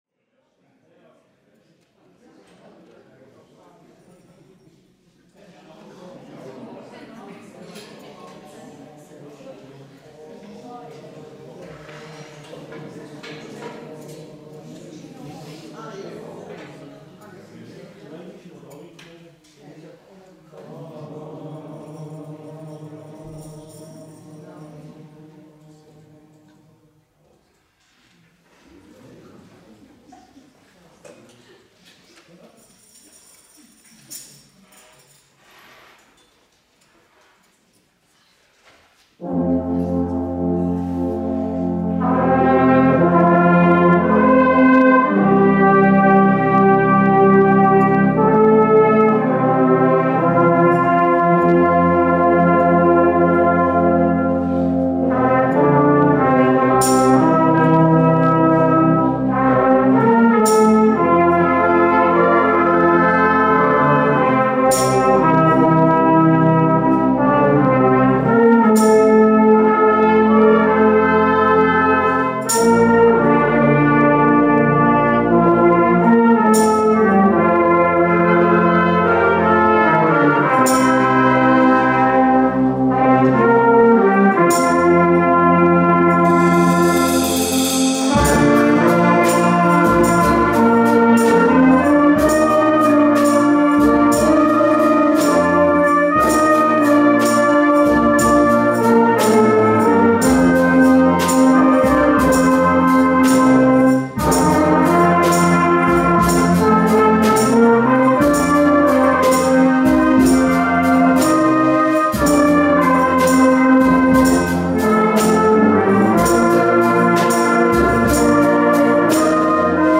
Adventskonzert_2024.mp3